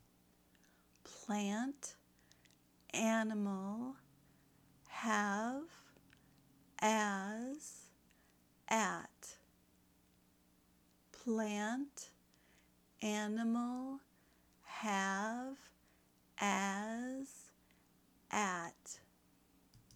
The Short “A” Sound
short-a-words.mp3